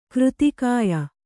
♪ křti kāya